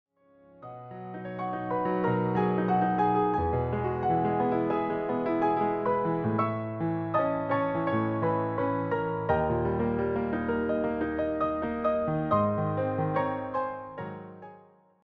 solo piano